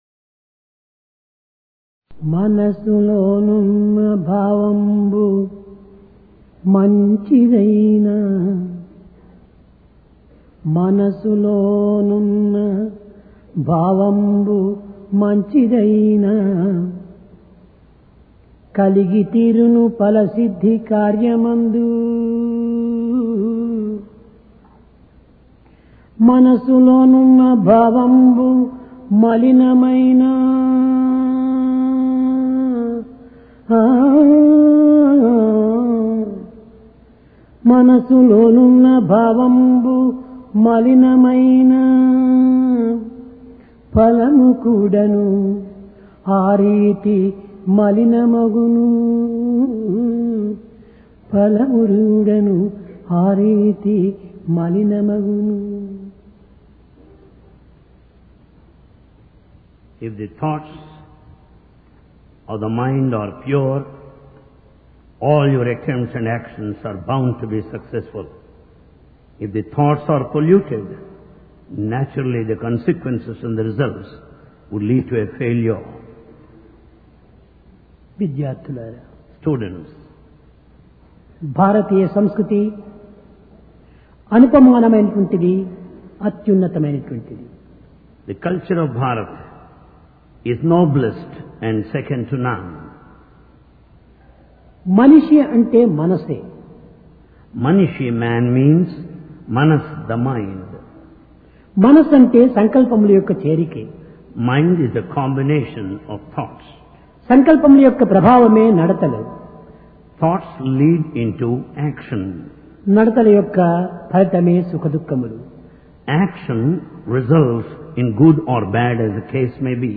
PRASHANTI VAHINI - DIVINE DISCOURSE 25 JUNE, 1996